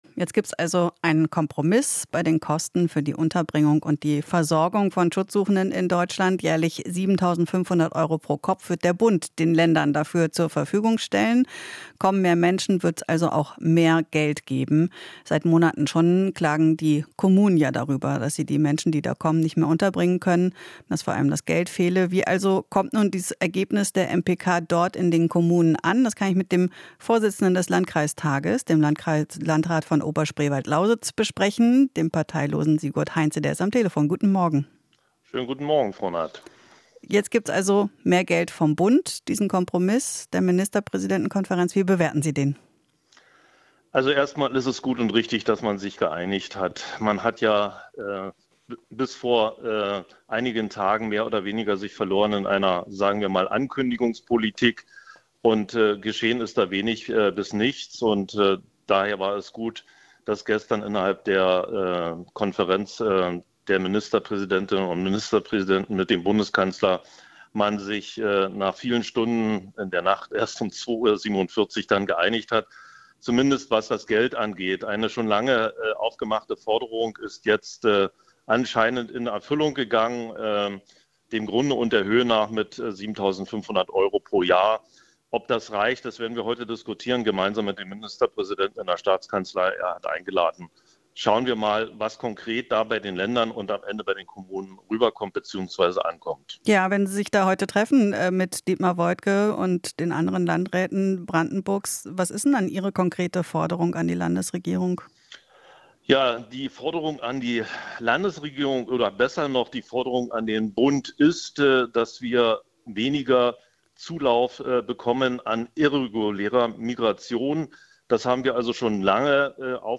Interview - Landrat: Brauchen weniger Migranten, die keinen Asylanspruch haben